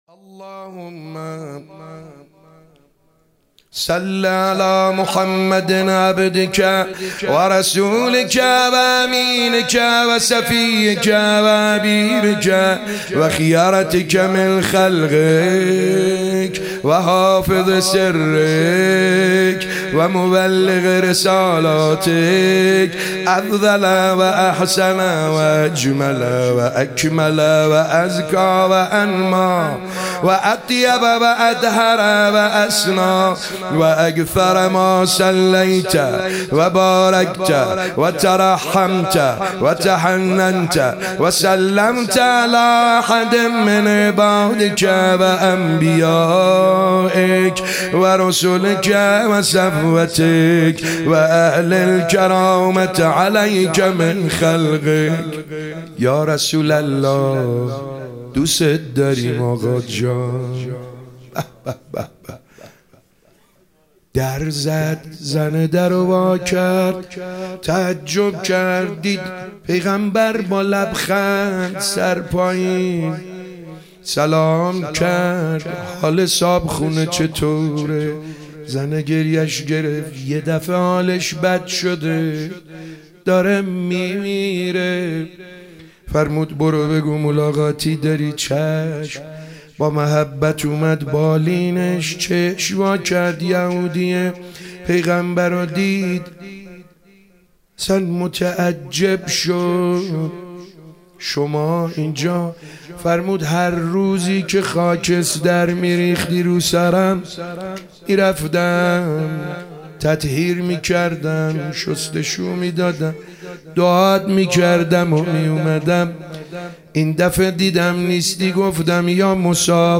شب 24 رمضان 97- روضه